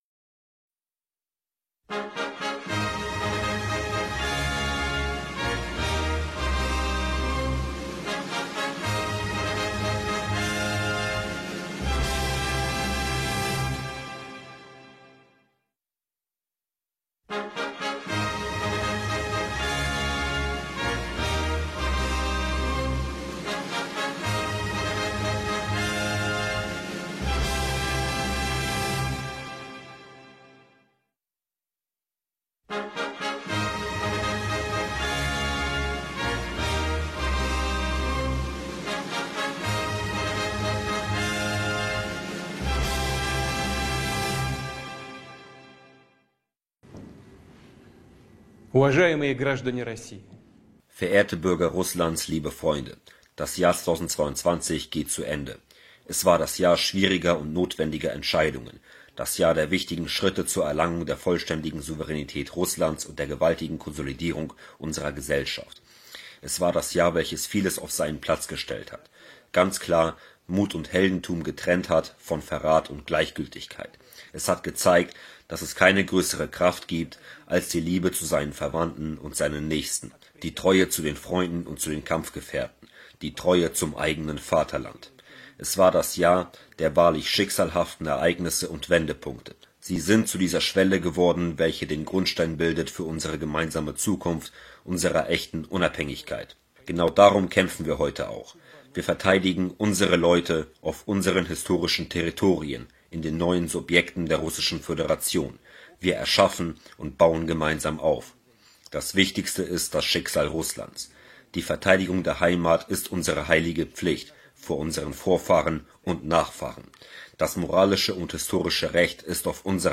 Er spricht von Liebe, mit Demut vor seinem Volk, mit Hochachtung zu den Soldaten und der Bevölkerung. Hier die Neujahrsrede komplett auf Deutsch übersetzt und unzensiert...